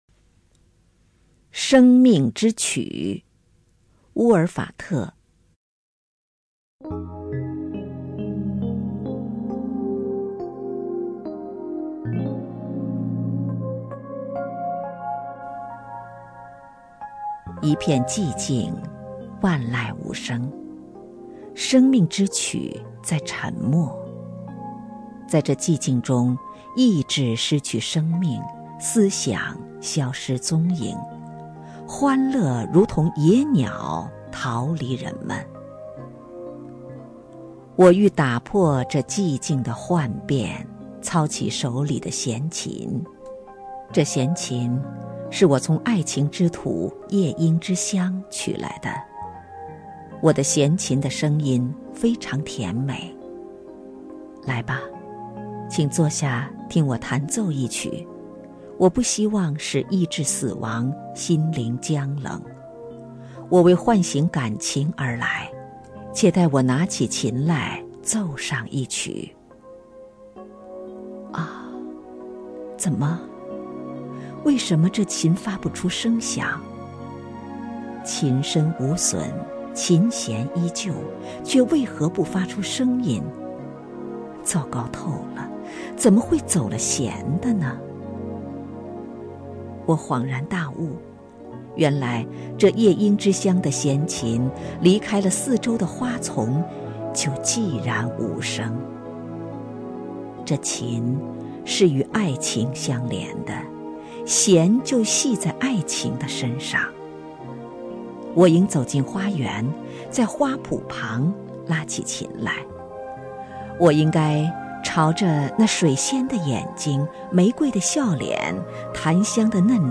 首页 视听 名家朗诵欣赏 王凯
王凯朗诵：《生活，让自己做主》(（法）蒙田)